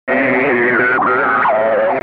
جلوه های صوتی
دانلود صدای رادیو 11 از ساعد نیوز با لینک مستقیم و کیفیت بالا